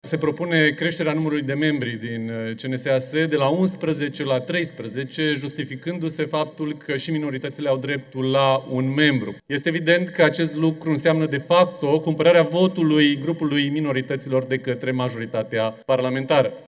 Deputatul Ilie Coleșa din partea AUR: „Acest lucru înseamnă, de fapt, o cumpărare a votului”